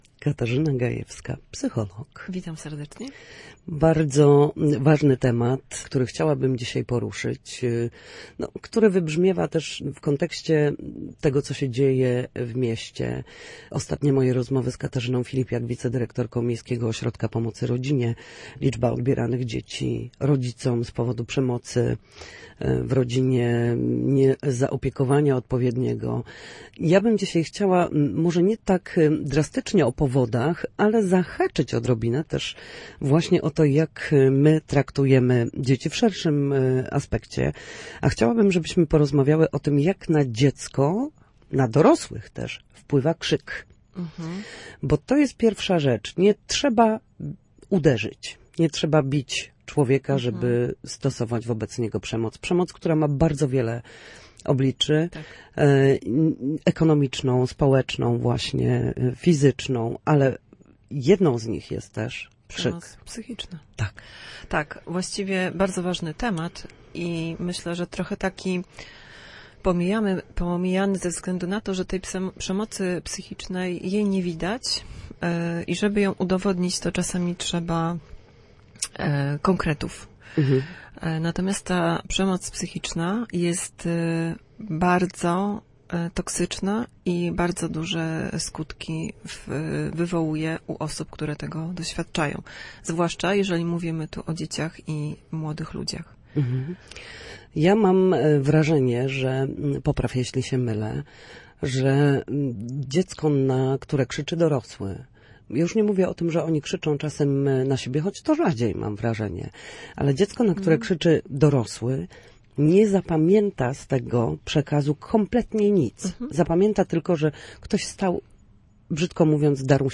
W każdą środę, w popołudniowym paśmie Studia Słupsk Radia Gdańsk, dyskutujemy o tym, jak wrócić do formy po chorobach i